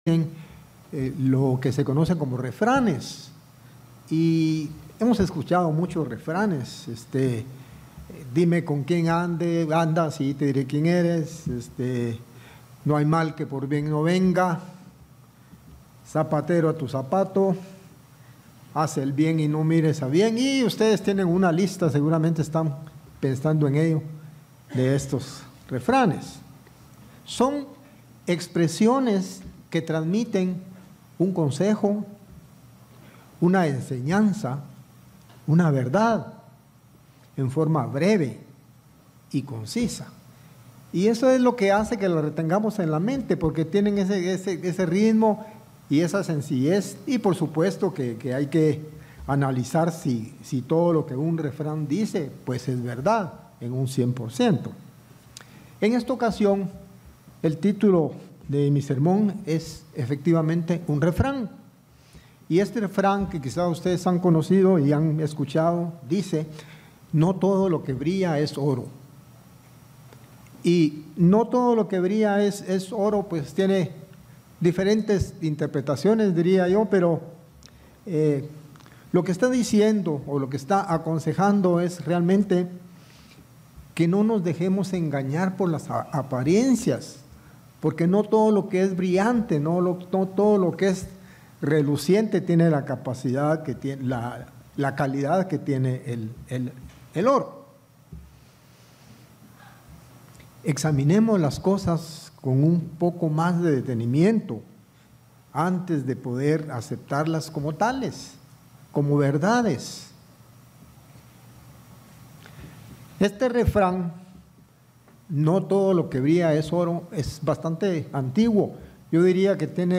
Sermones
Given in Ciudad de Guatemala